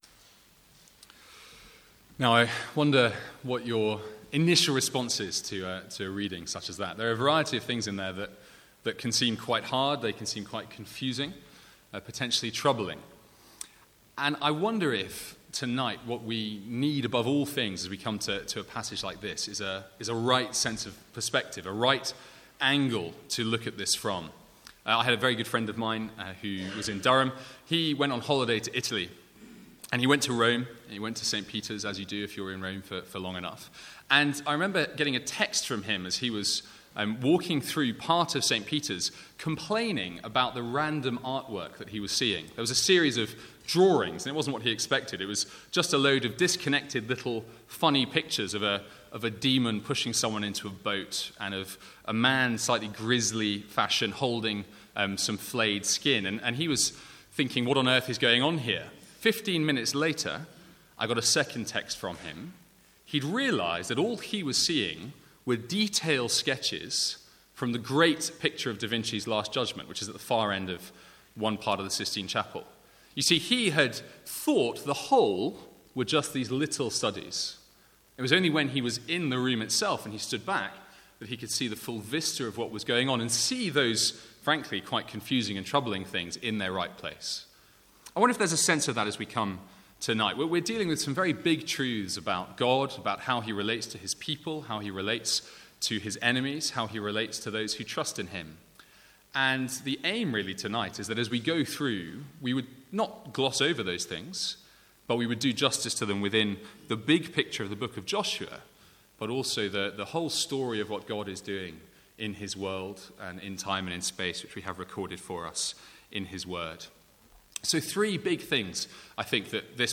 From the Sunday evening series in Joshua.
Sermon Notes